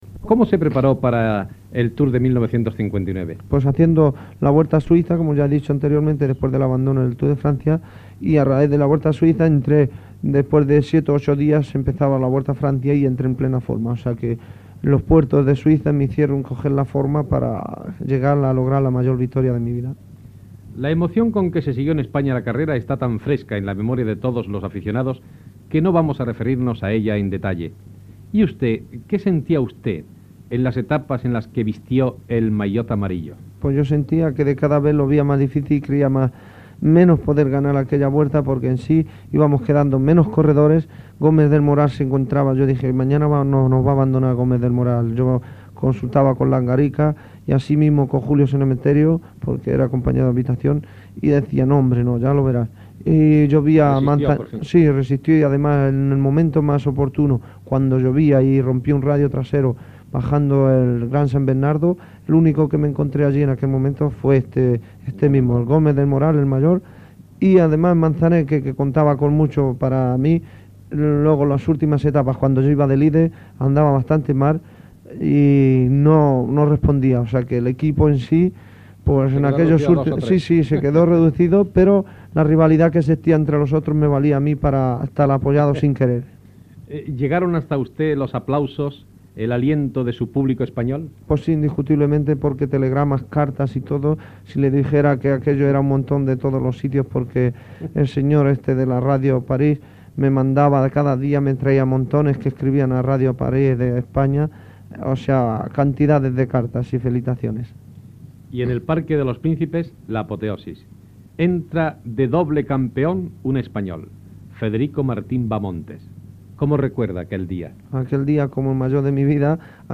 Entrevista al ciclista Federico Martín Bahamontes qui recorda com va guanyar el Tour de França a l'any 1959